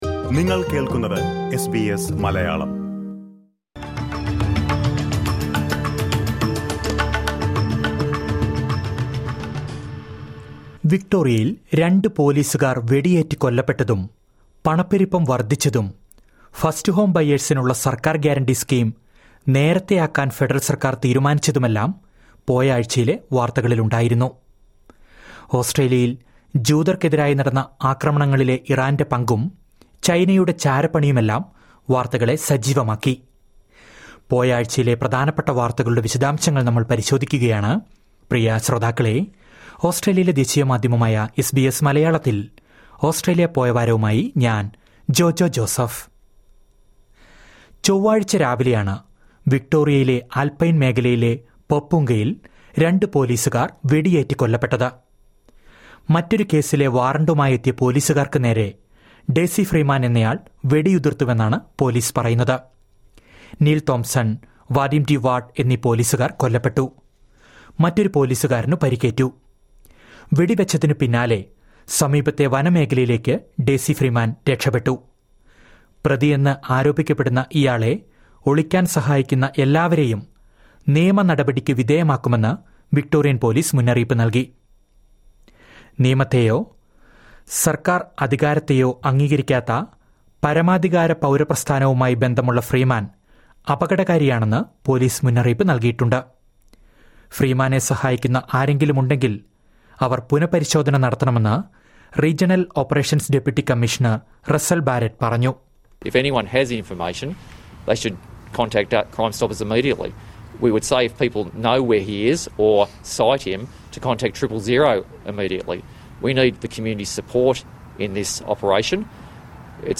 ഇക്കഴിഞ്ഞയാഴ്ചയിലെ ഓസ്ട്രേലിയയിലെ ഏറ്റവും പ്രധാന വാർത്തകൾ കേൾക്കാം, ചുരുക്കത്തിൽ...